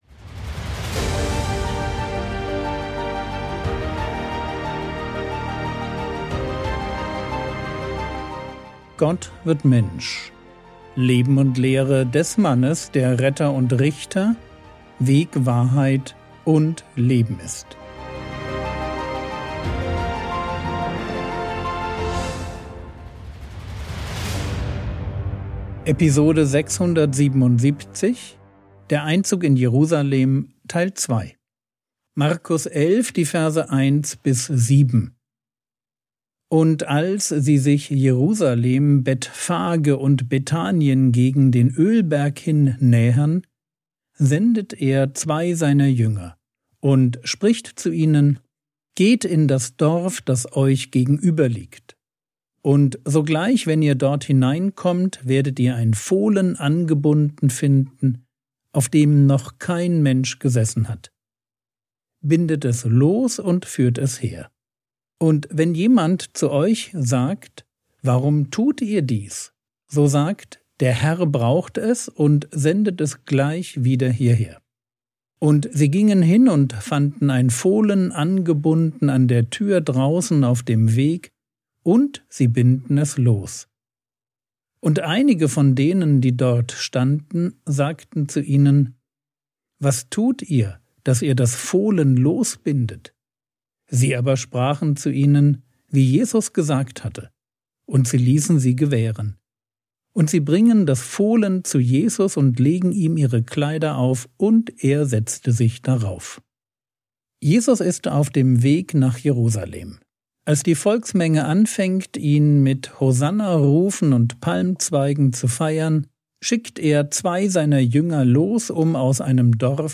Episode 677 | Jesu Leben und Lehre ~ Frogwords Mini-Predigt Podcast